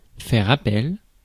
Prononciation
Prononciation : IPA: /fɛ.ʁ‿a.pɛl/ Le mot recherché trouvé avec ces langues de source: français Les traductions n’ont pas été trouvées pour la langue de destination choisie.